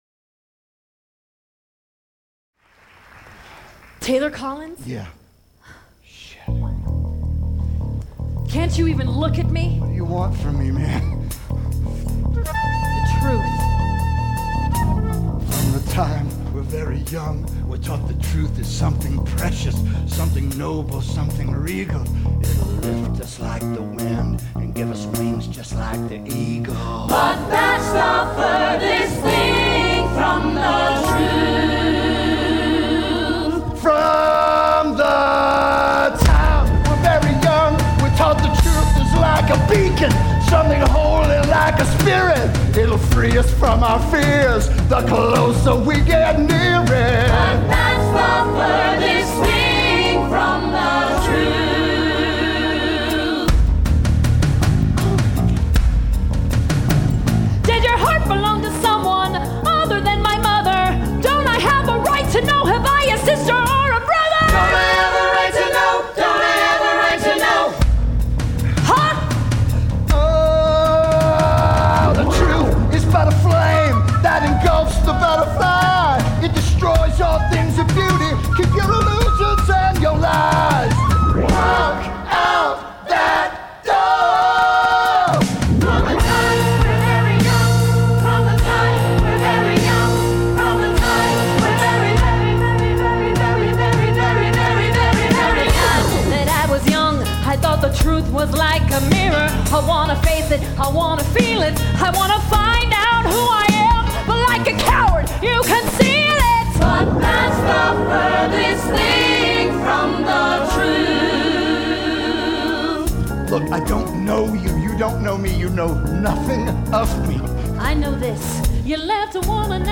in the Denver production of Brooklyn!